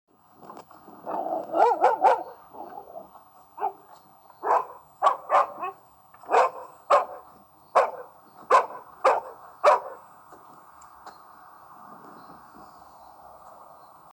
Perros en la calle CARTAGO